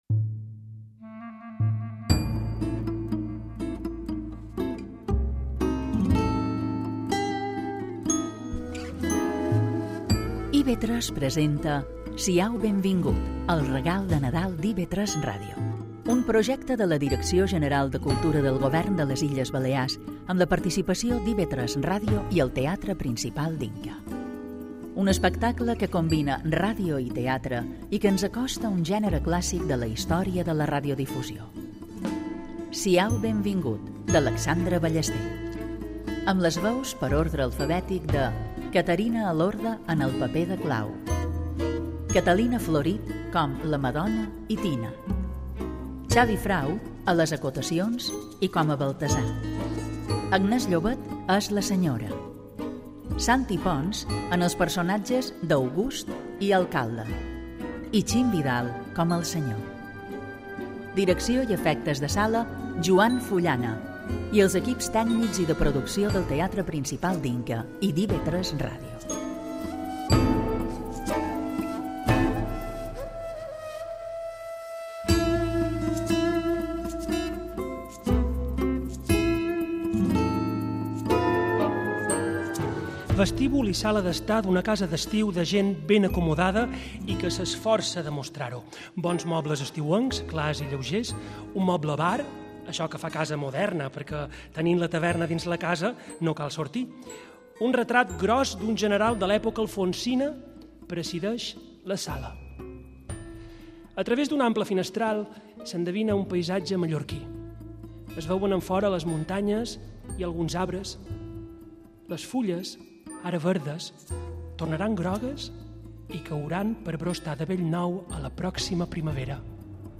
Lectura radiofònica de l’obra d’Alexandre Ballester enregistrada, en directe, al Teatre Principal d’Inca.